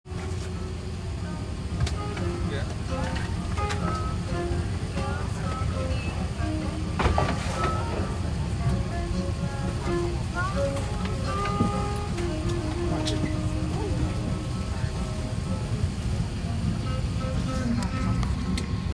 soothing sounds